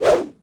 footswing1.ogg